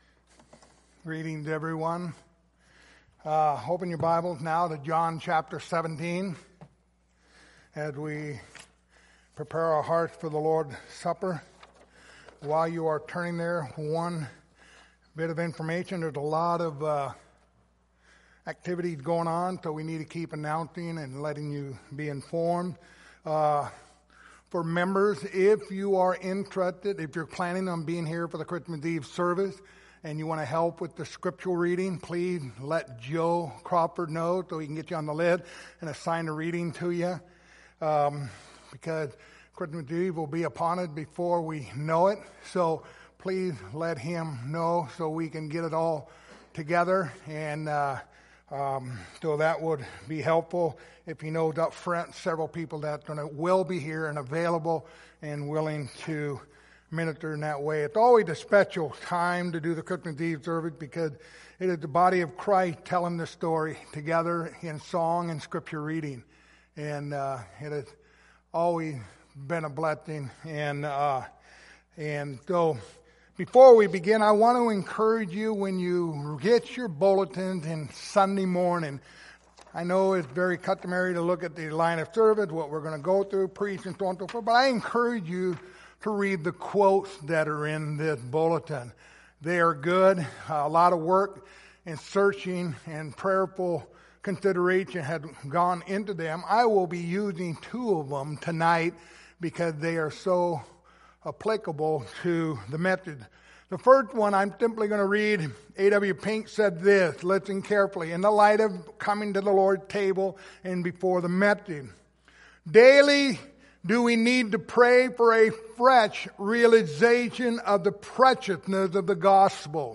Passage: John 17:1-10 Service Type: Lord's Supper